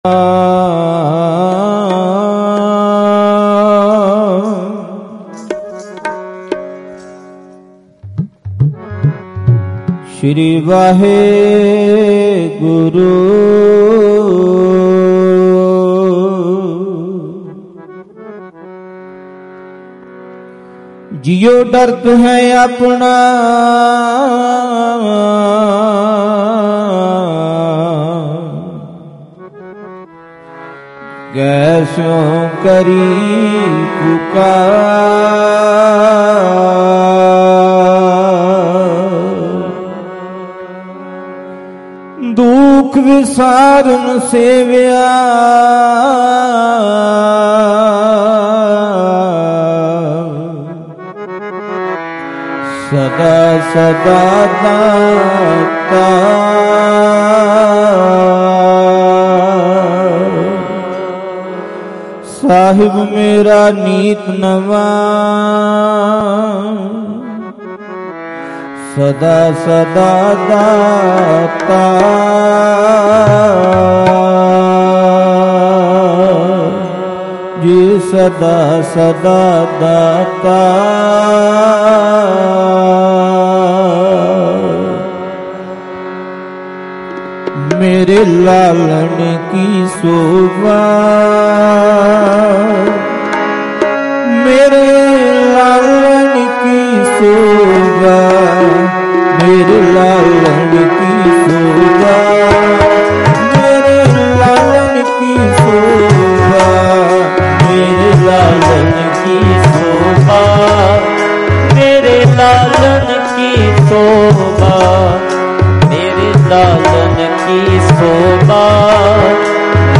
Live Gurmat Samagam Phagwara, Jalandhar 7 Nov 2025